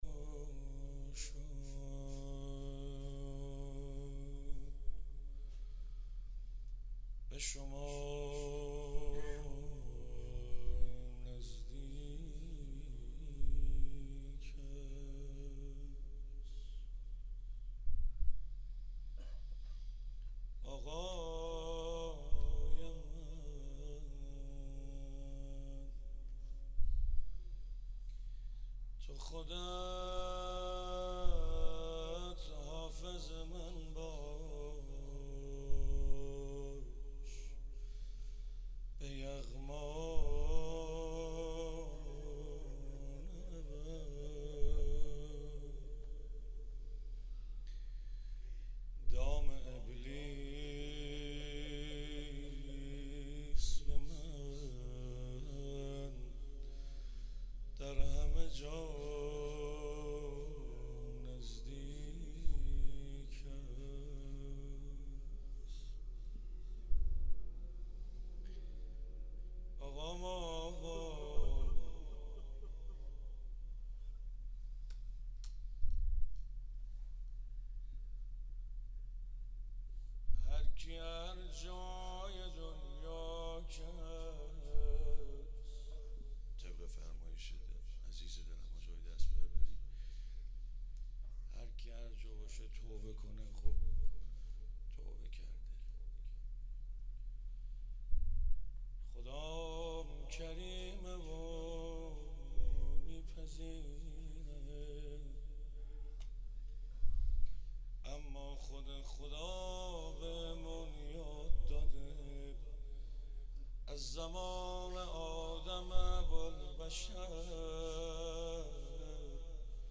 شب 4 صفر 95_روضه حضرت رقیه سلام الله علیها